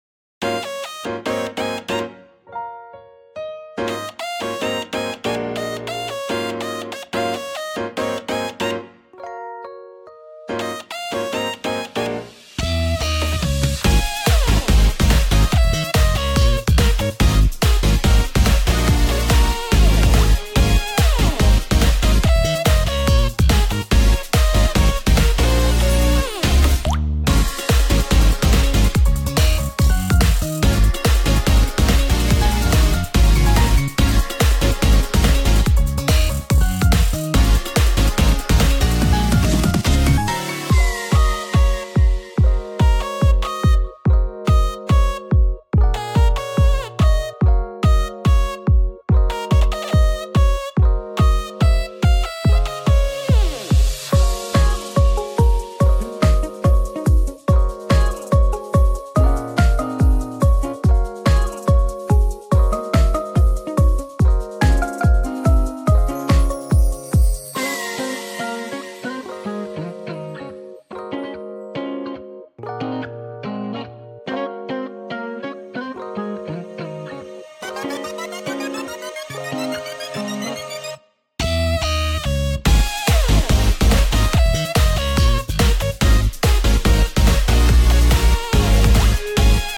二人声劇「クレセント・アイランド」